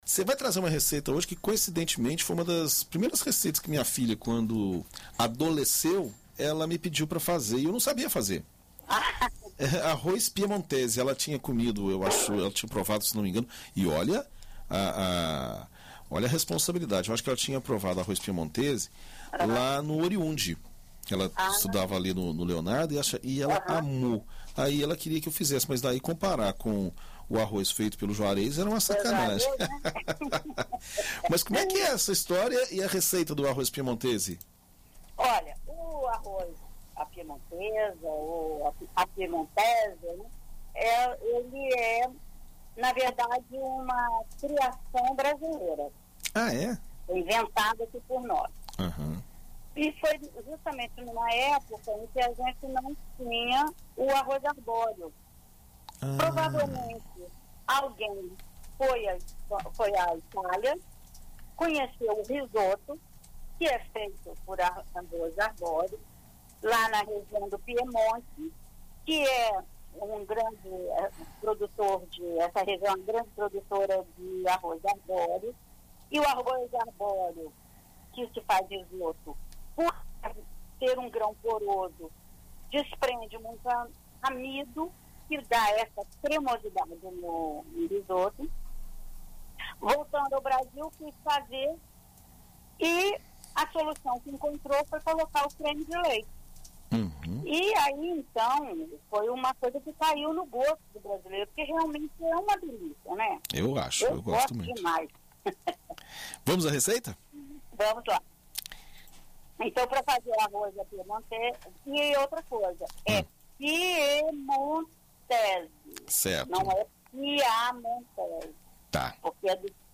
Na coluna Conversa de Cozinha desta sexta-feira
na BandNews FM Espírito Santo